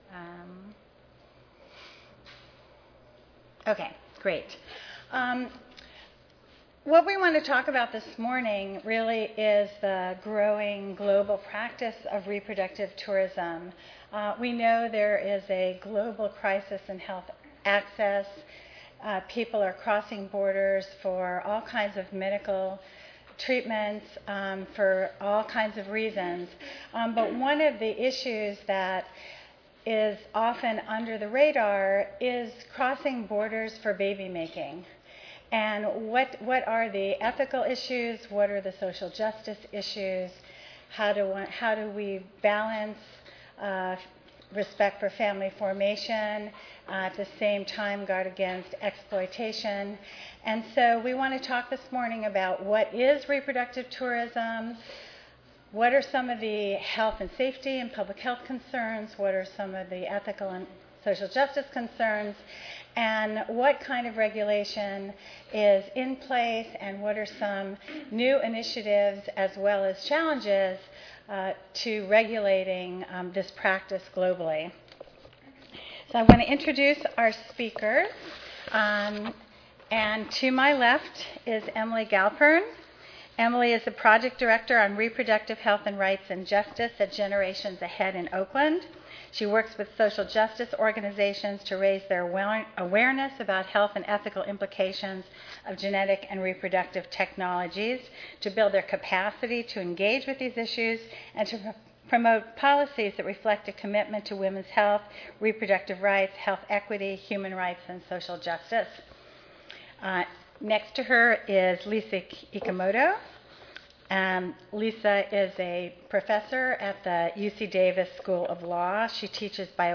4115.0 Reproductive Tourism: Ethical and Health Concerns Tuesday, October 28, 2008: 10:30 AM Oral The surge in reproductive tourism in our increasingly globalized world requires close attention by public health advocates.